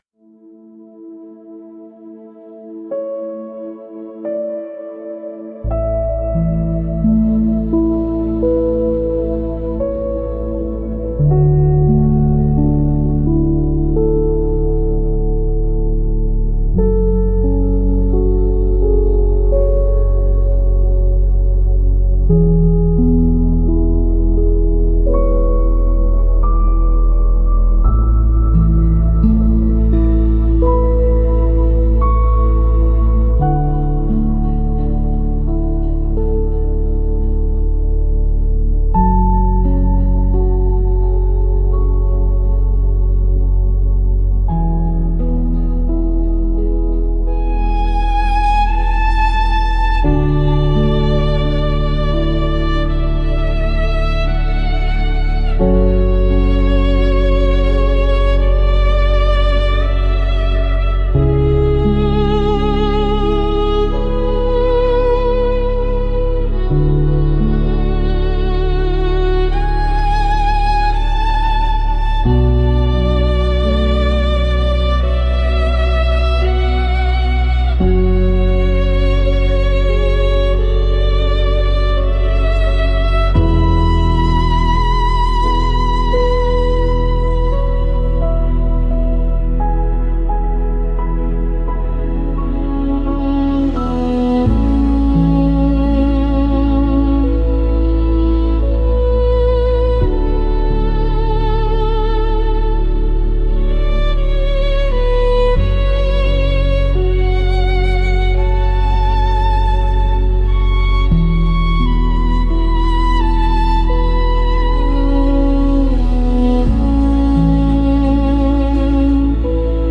高空轻盈睡眠环境，超慢29 BPM节奏，10公里稀薄空气垫，全景慢速平移，风暴之上静谧，轻柔蒸汽层，极致安全防护体验，瞬间入睡时仿佛漂浮太空，无累积低动态无缝平缓过渡 ...
高空轻盈睡眠环境，超慢29 BPM节奏，10公里稀薄空气垫，全景慢速平移，风暴之上静谧，轻柔蒸汽层，极致安全防护体验，瞬间入睡时仿佛漂浮太空，无累积低动态无缝平缓过渡 如果无法播放，请点击此处在新窗口打开 ## 万米云巅宁静 **提示词：** altitude airy sleep ambient, ultra slow 29 BPM, 10km thin air pads, panoramic slow pans, above-storm peace, gentle vapor layers, ultimate feeling of safety and protection, feels like drifting through space while falling asleep instantly, no build-up constant low dynamics seamless flat progression **科学解释：** 高空低压感减少思绪，氧合优化，REM前过渡顺滑